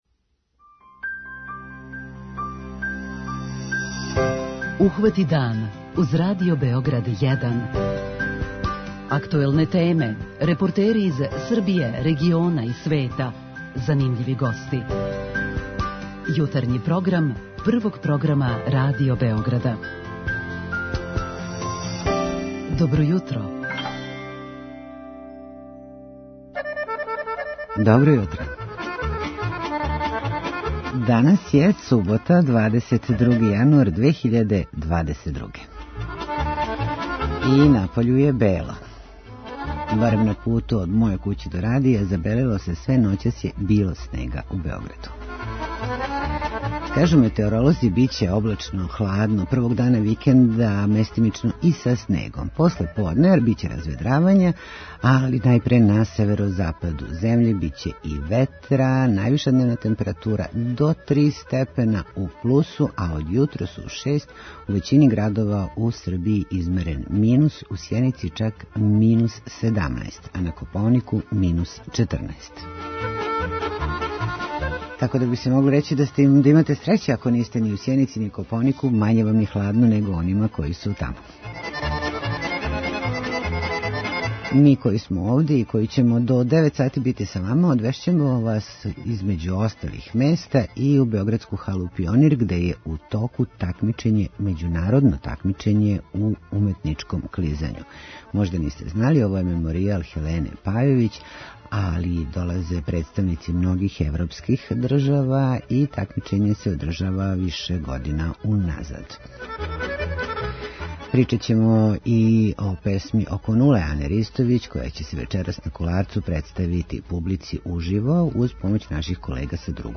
Наши дописници јављају о - органској производњи у Војводини, кућама за младе парове у Прокупљу, новистима у Чачку, Лесковцу, Новом Саду.